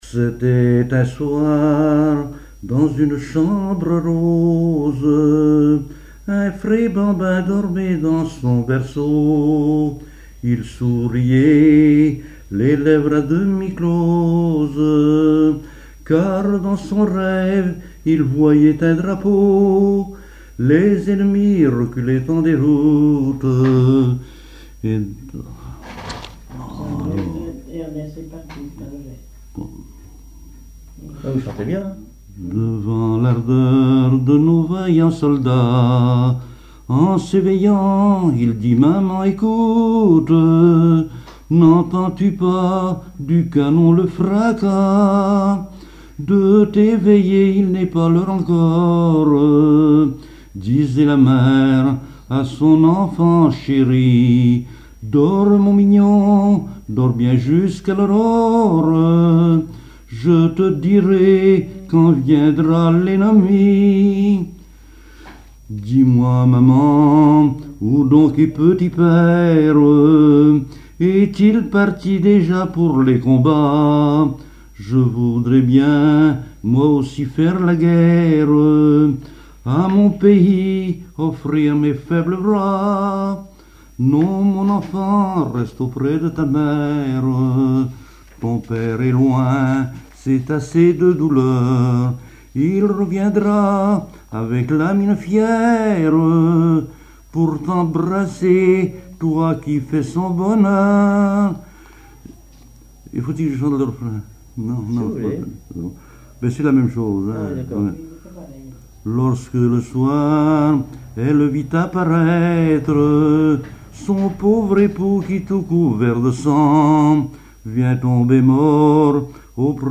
Berceuse
Genre strophique
Catégorie Pièce musicale inédite